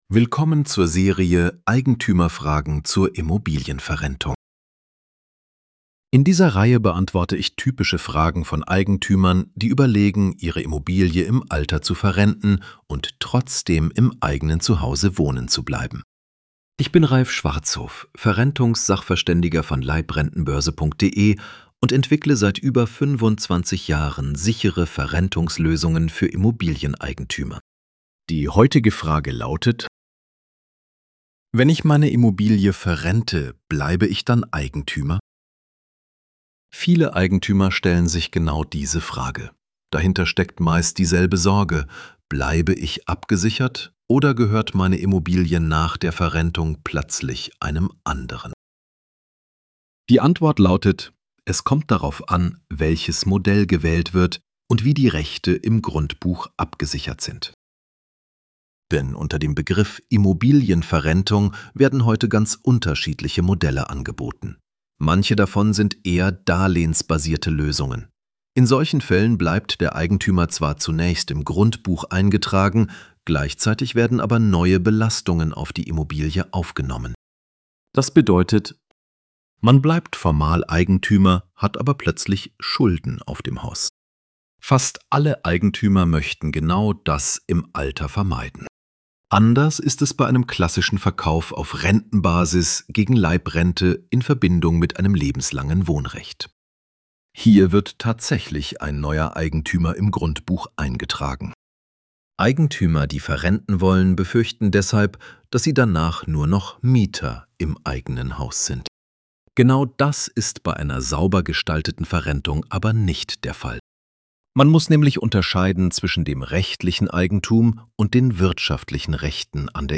Wenn Sie den Artikel lieber anhören möchten, finden Sie hier die gesprochene Fassung des Beitrags. Der Text wurde für das Audio-Format sprachlich angepasst.